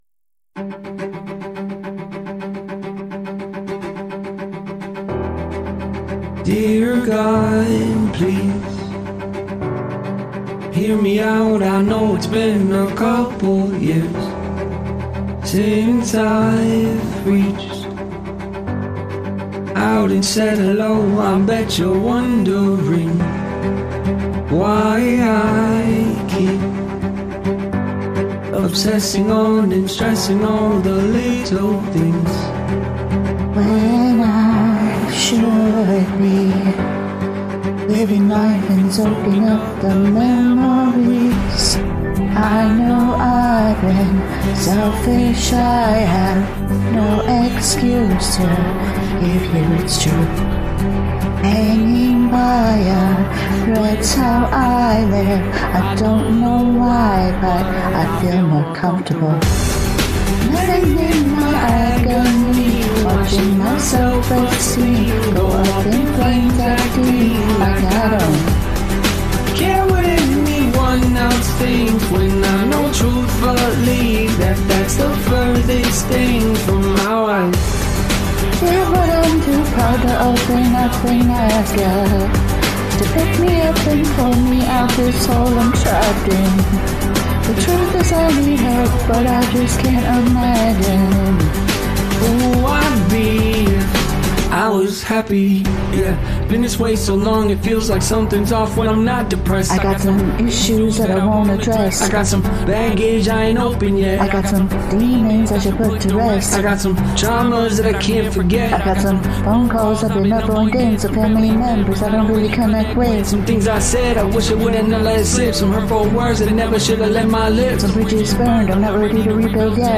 The female vocal is in need of EQ & de-essing: